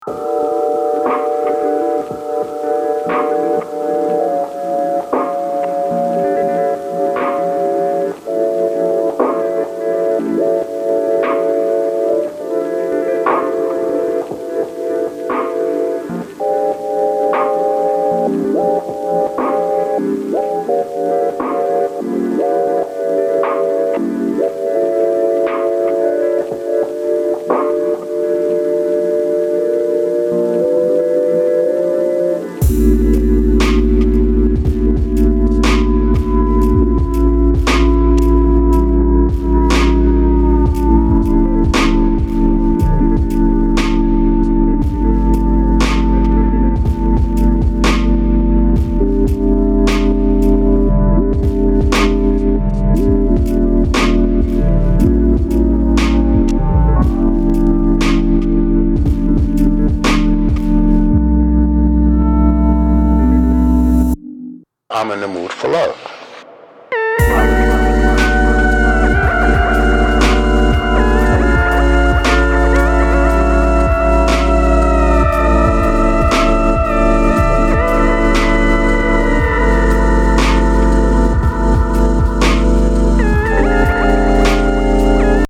Electro Electronix Breaks Breakbeat